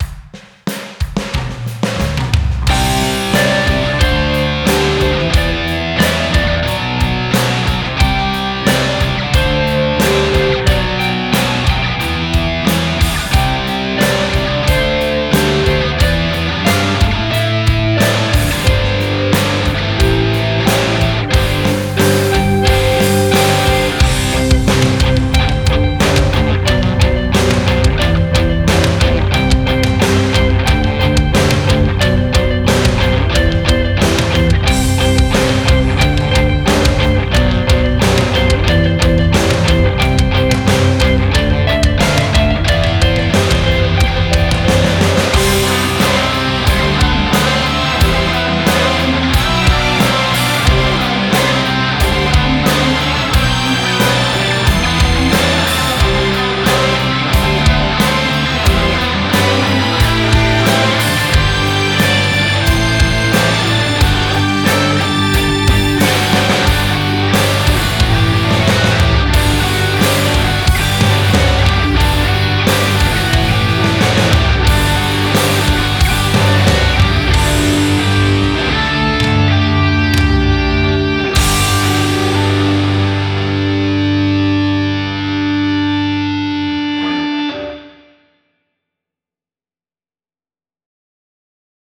02_happybirthday_offvocal.wav